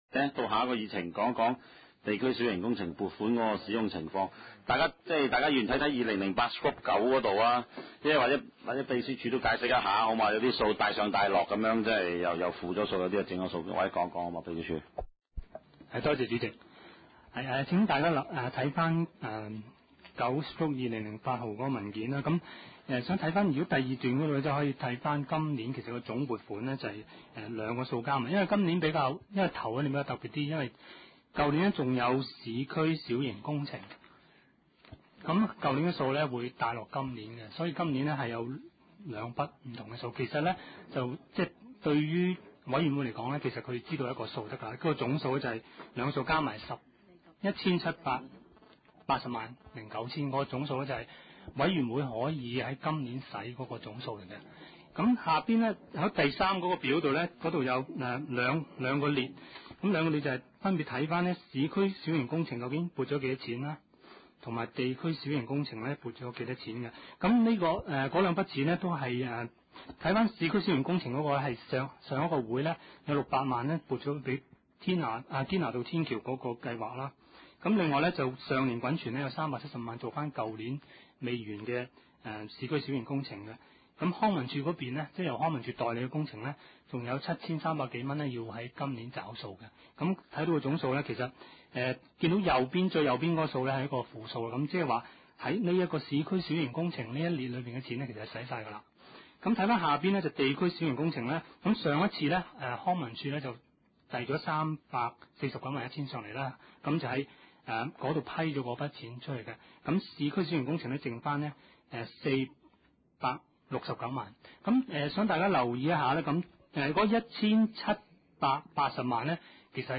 地區工程及設施管理委員會第三次會議
灣仔民政事務處區議會會議室